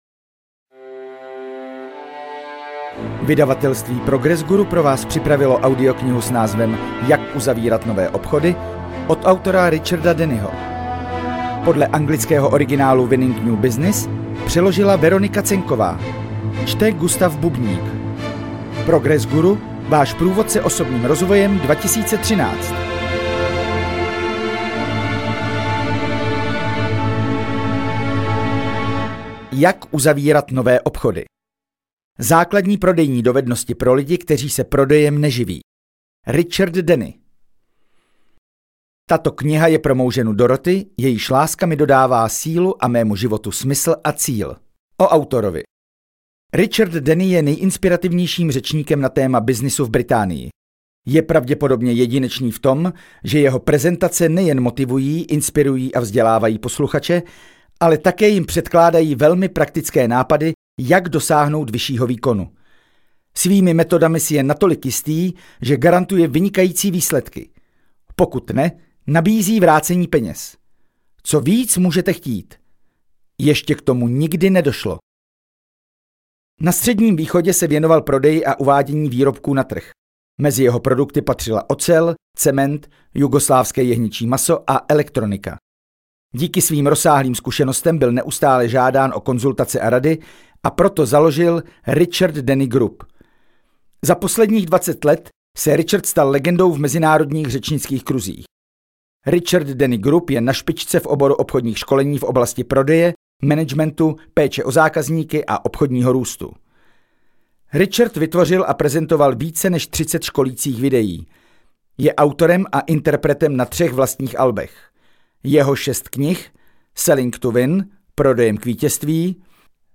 AudioKniha ke stažení, 14 x mp3, délka 3 hod. 55 min., velikost 214,6 MB, česky